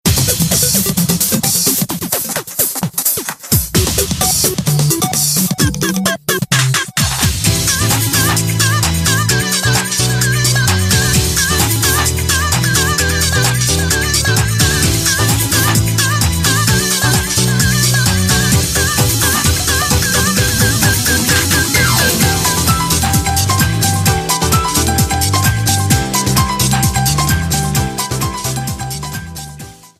Trimmed and fadeout